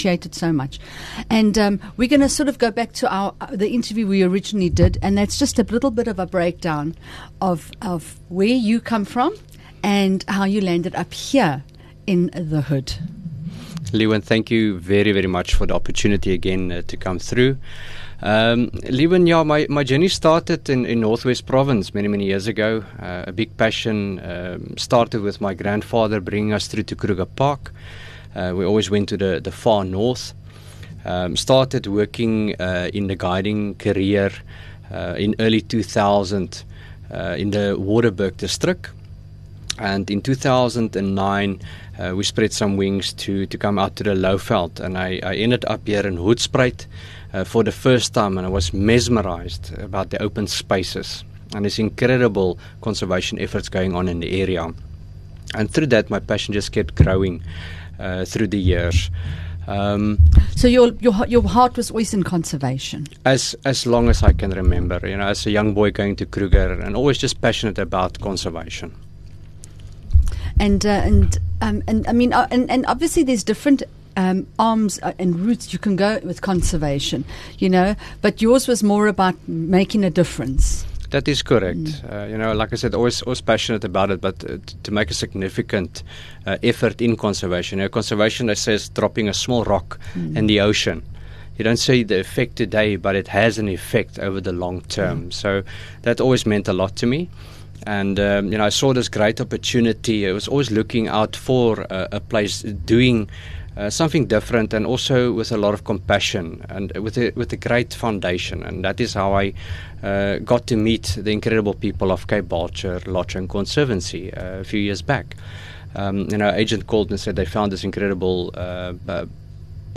Radio Interviews